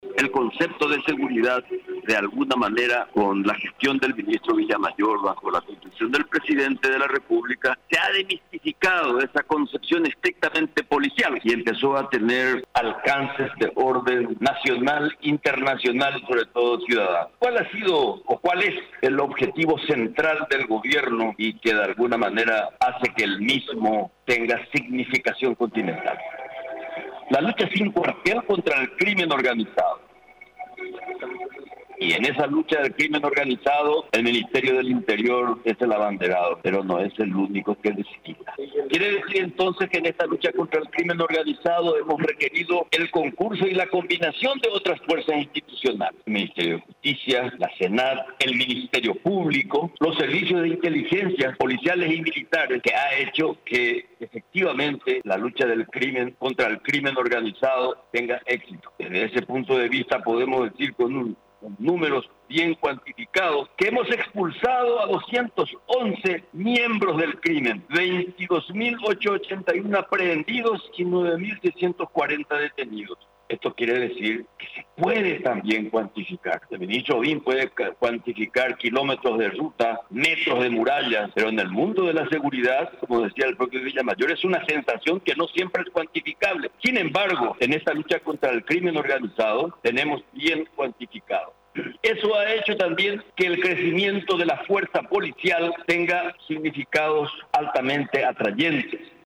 El ministro del Interior, Euclides Acevedo, durante el informe de gestión, destacó los logros y resultados que se producido en el combate contra el crimen organizado y el terrorismo.